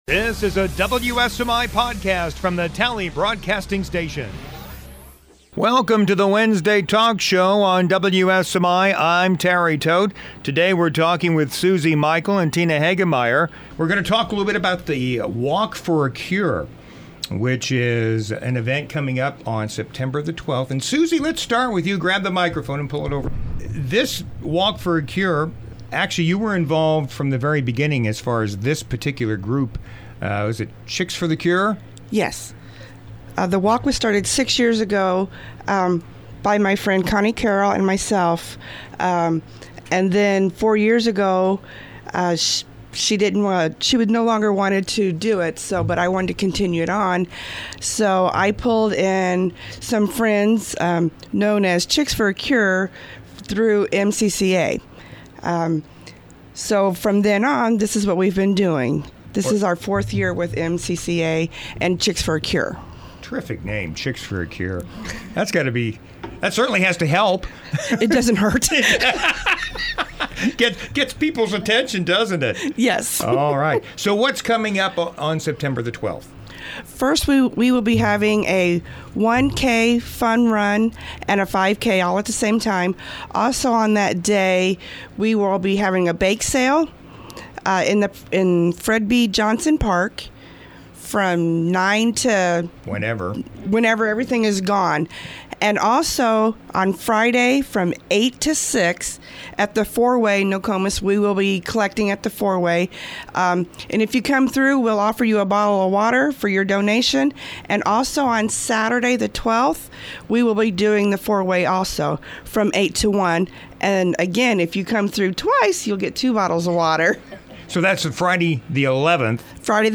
Wednesday Talk Show